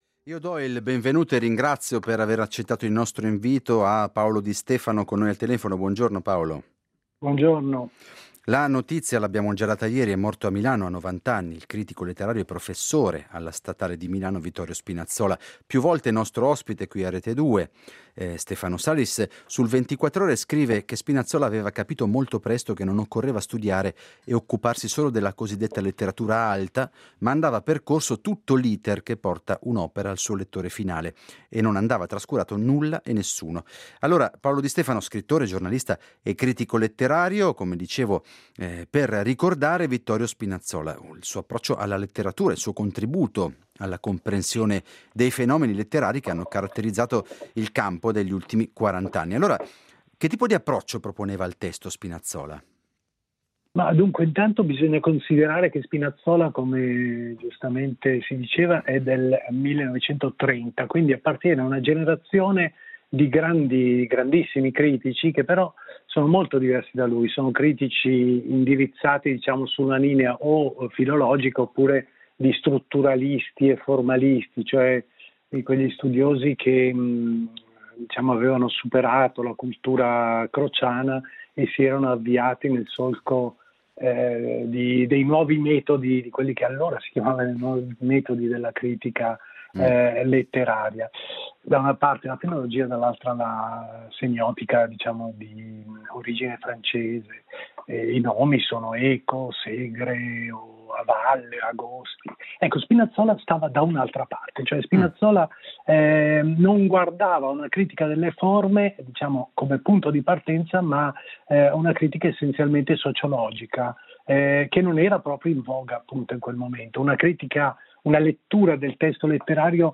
Ne parliamo con Paolo Di Stefano, scrittore giornalista e critico letterario.